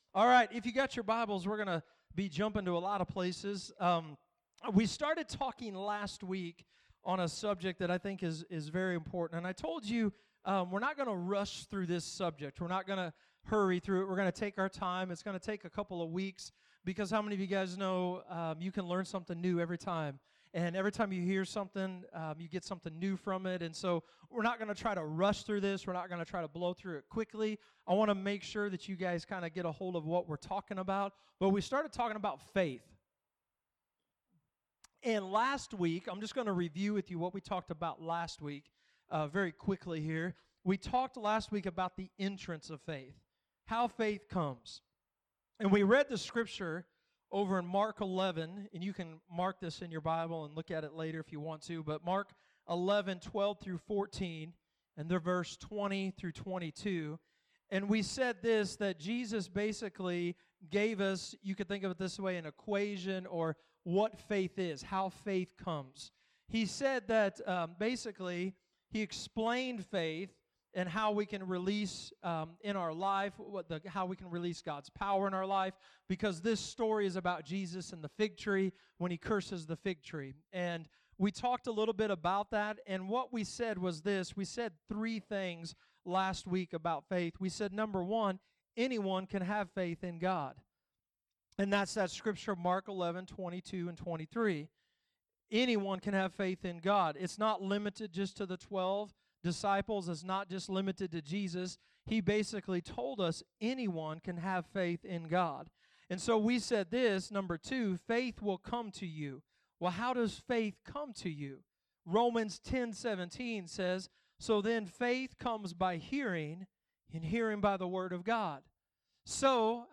Sermons | LifePointe Church